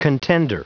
Prononciation du mot contender en anglais (fichier audio)
Prononciation du mot : contender